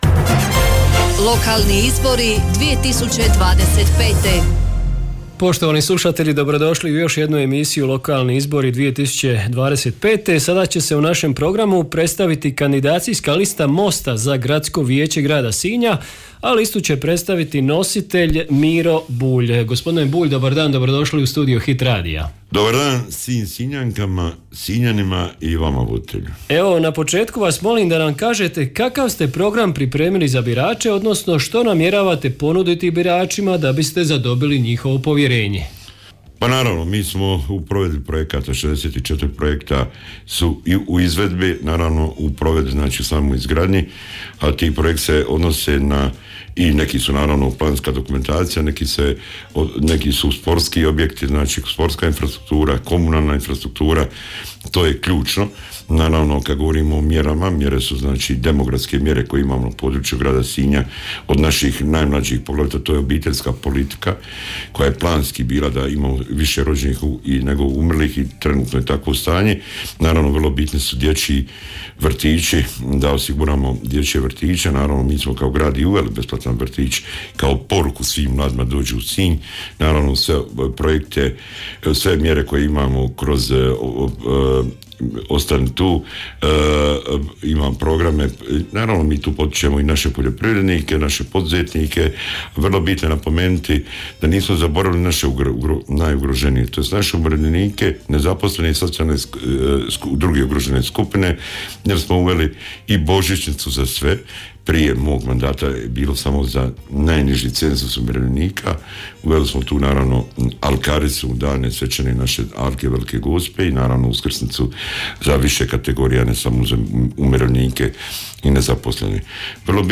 Sve kandidacijske liste i svi kandidati za načelnike odnosno gradonačelnike tijekom službene izborne kampanje imaju pravo na besplatnu emisiju u trajanju do 10 minuta u studiju Hit radija.
Danas je izborni program kandidacijske liste Mosta za Gradsko vijeće Grada Sinja predstavio nositelj liste Miro Bulj.